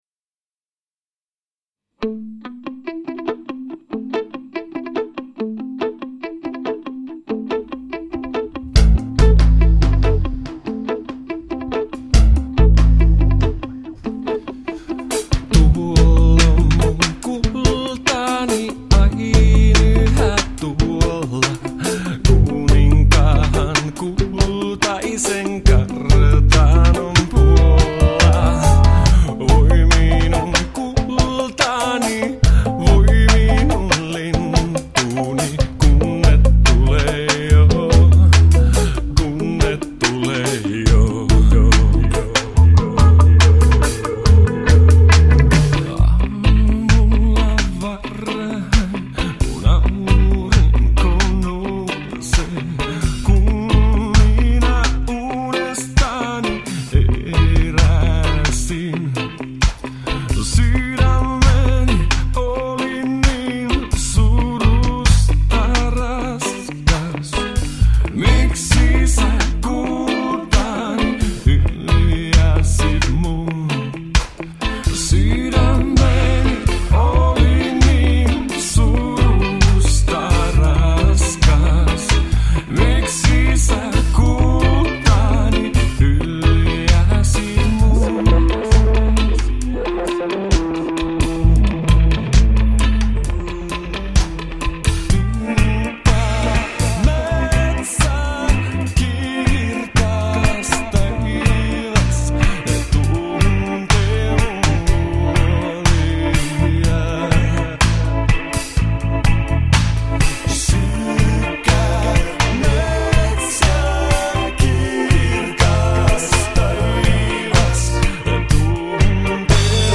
laulu, taustalaulu, basso
kitarat, taustalaulu
rummut, taustalaulu